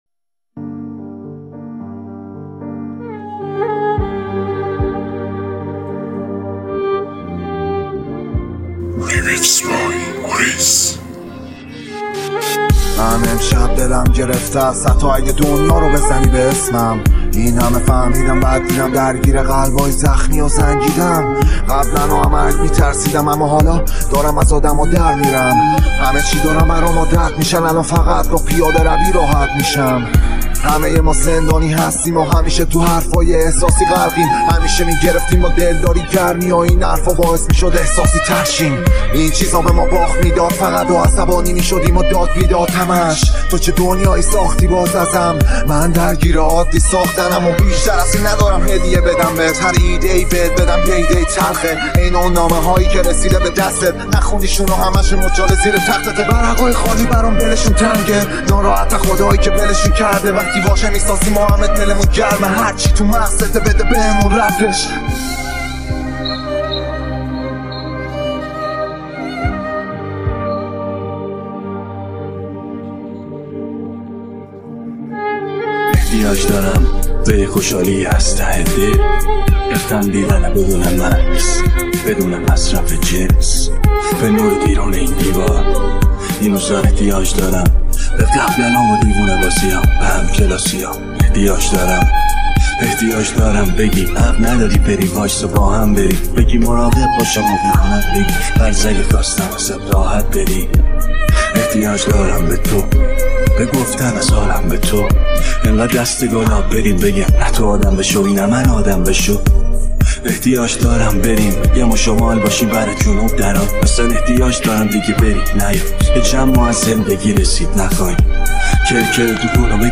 رپ
غمگین